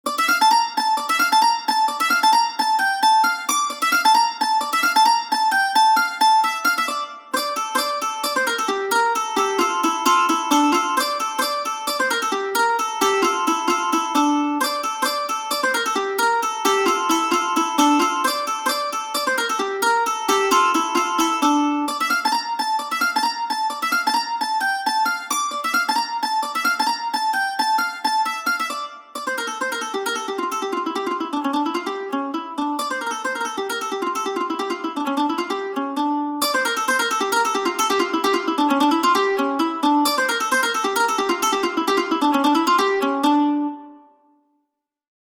Piece featuring a 3 layer solo mandolin